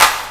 050 - Clap-3.wav